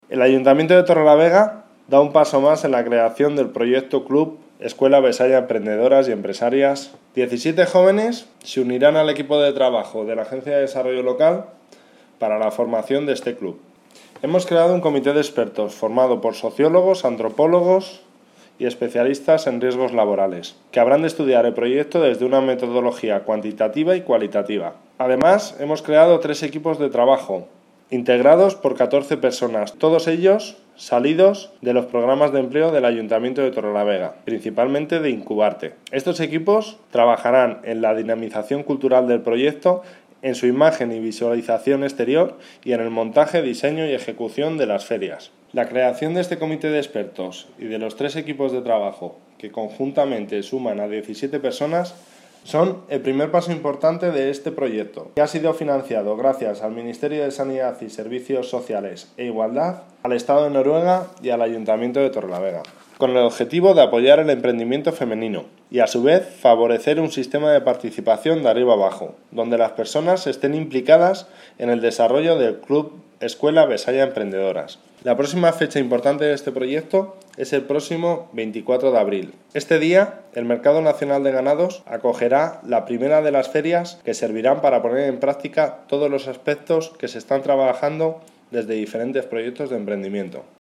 Javier L. Estrada, concejal de Empleo